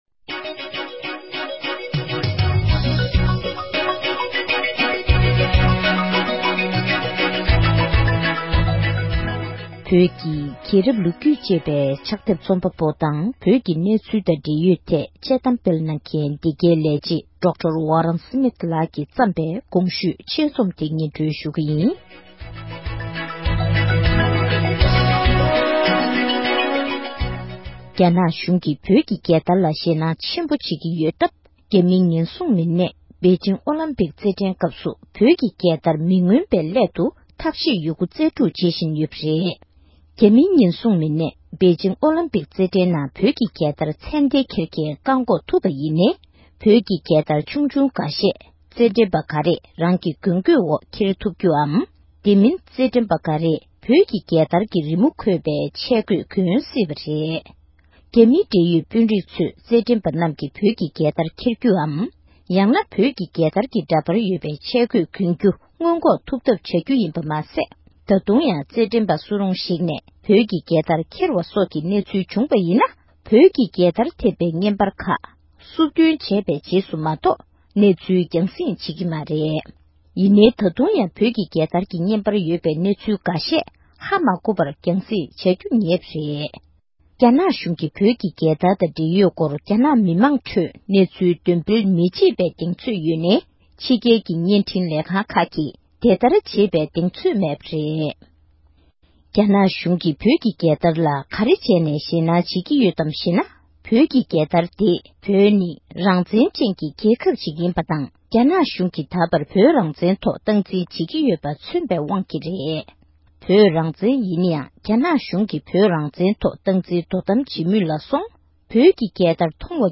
བོད་སྐད་ཐོག་ཕབ་བསྒྱུར་གྱིས་སྙན་སྒྲོན་ཞུས་པར་གསན་རོགས་གནང༌༎